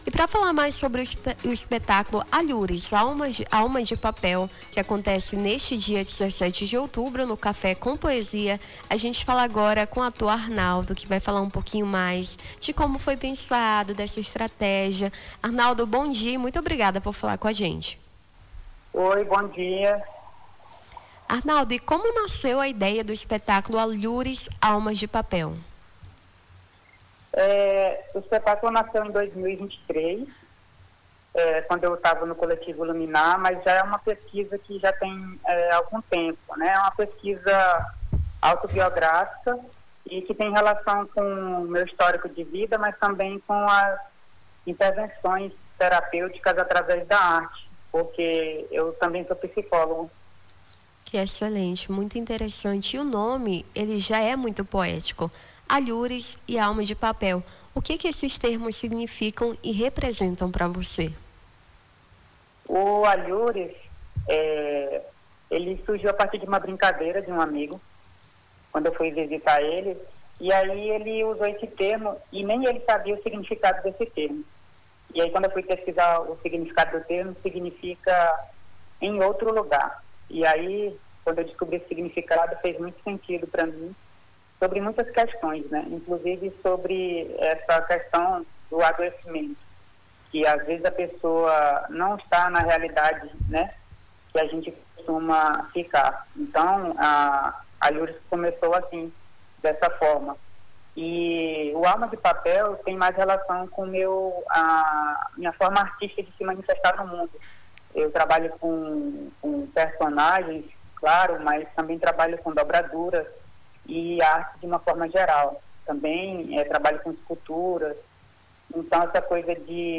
Nome do Artista - CENSURA - ENTREVISTA (ESPETACULO ALHURES) 17-10-25.mp3